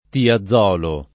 [ tia zz0 lo ]